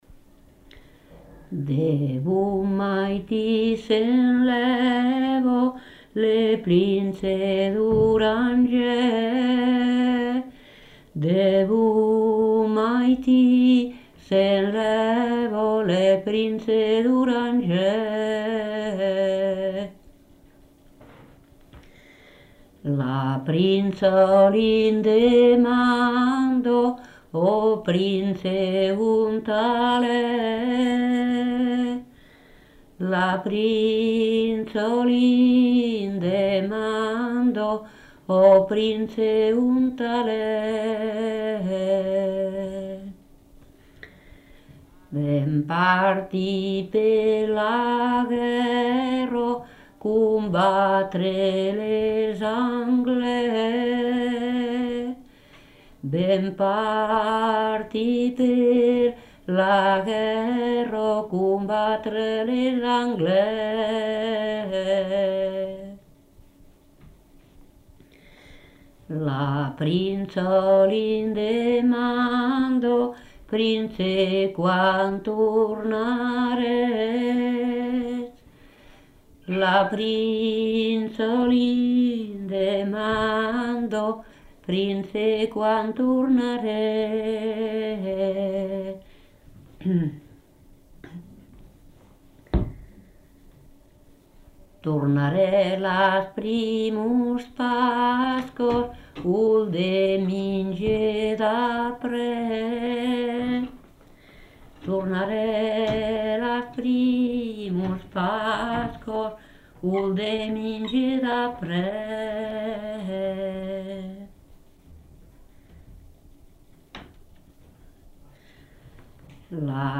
Aire culturelle : Couserans
Lieu : Montseron
Genre : chant
Effectif : 1
Type de voix : voix de femme
Production du son : chanté